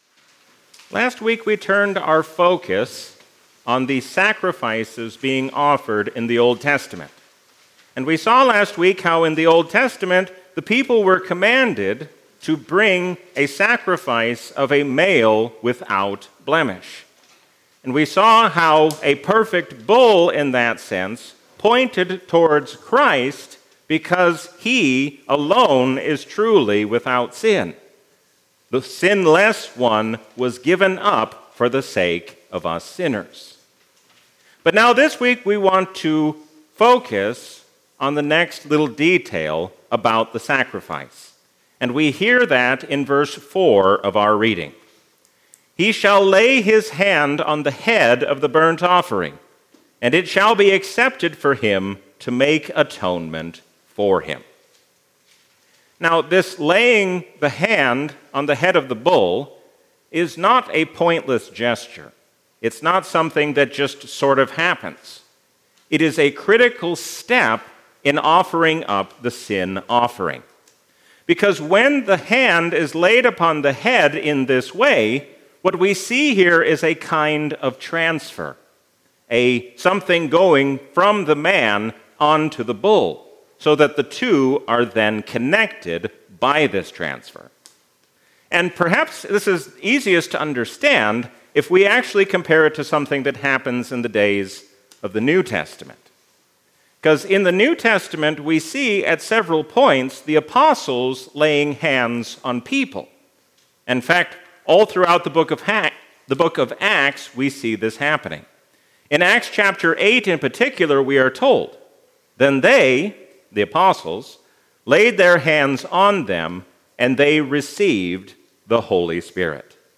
A sermon from the season "Lent 2025." God shows His greatness by showing us mercy in Jesus Christ.